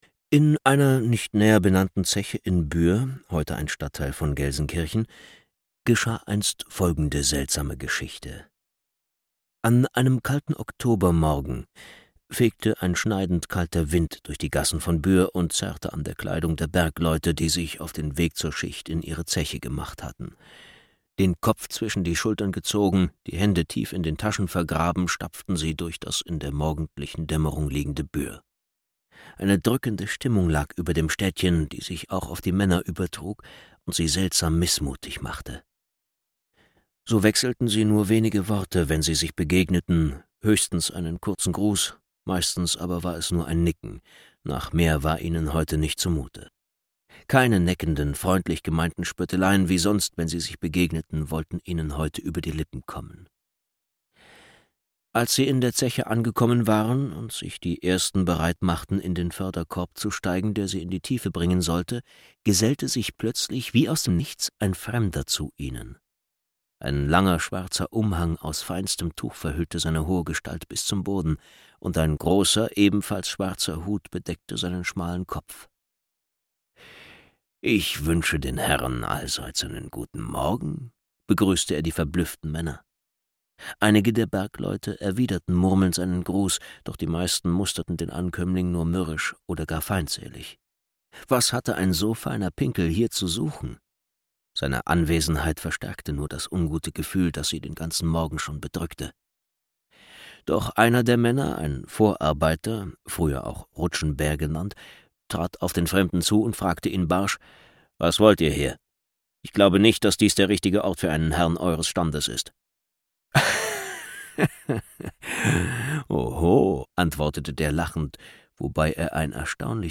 Schlagworte Hörbuch; Märchen/Sagen • NRW • Ruhrgebiet • Ruhrgebiet, Literatur; Sagen • Sagen